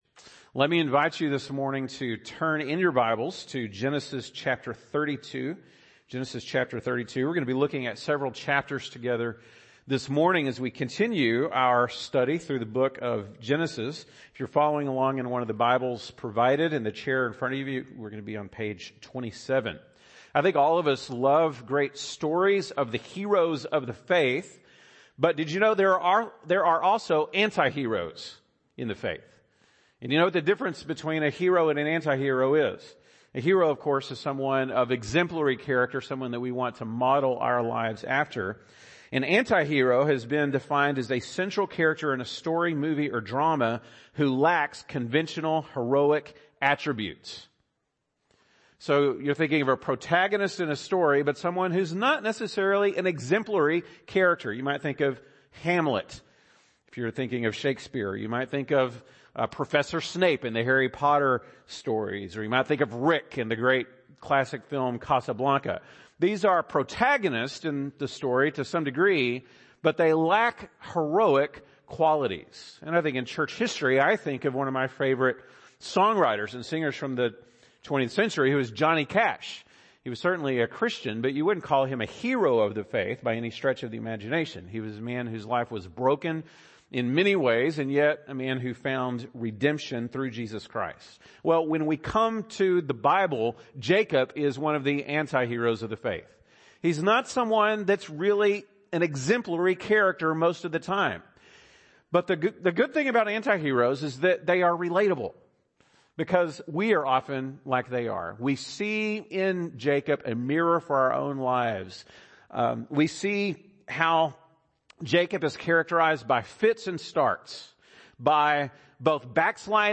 February 21, 2021 (Sunday Morning)